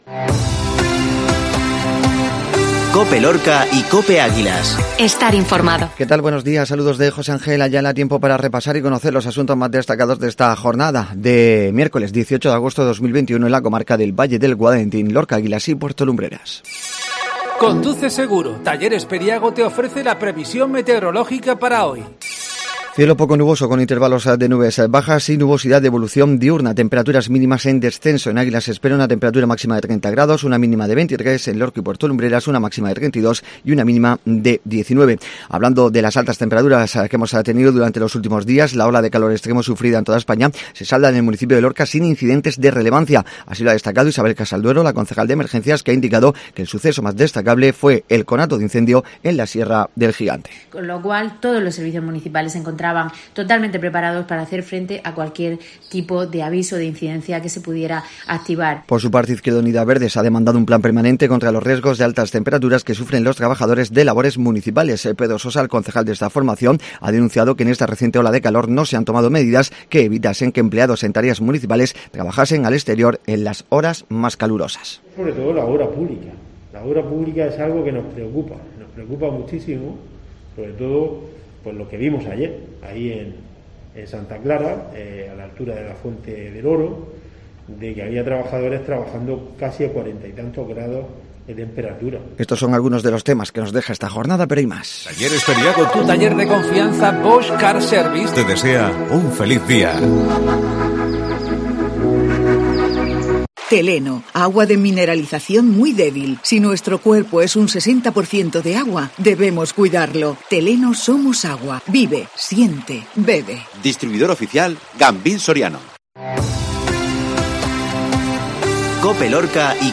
AUDIO: INFORMATIVO MATINAL MIÉRCOLES 1808